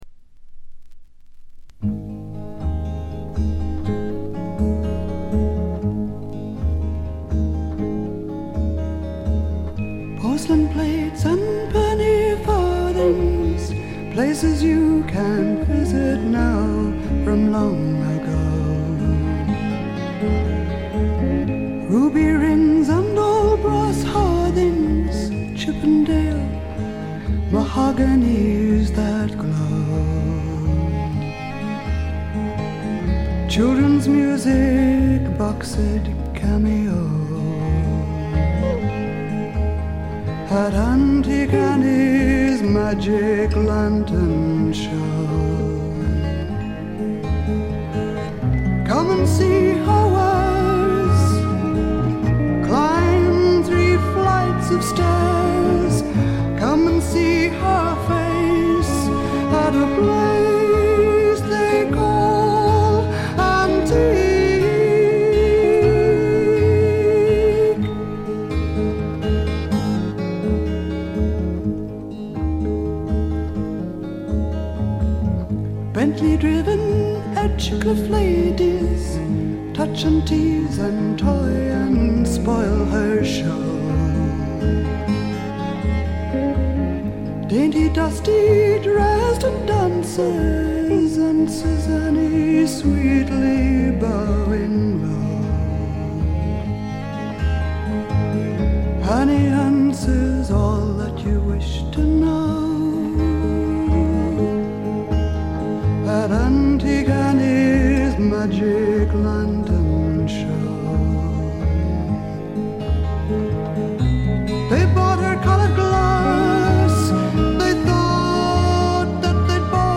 オーストラリアの女性シンガー・ソングライター／アシッド・フォーキーが残した大傑作です。
実に魅力的なアルト・ヴォイスの持ち主で、初めて聴く方はまずはこの声にやられてしまうことでしょう。
この強力な声を武器にシンプルなバックを従えて、フォーキーでジャジーでアシッドでダークなフォークロックを展開しています。
試聴曲は現品からの取り込み音源です。
Vocals ?
Guitar ?
Bass ?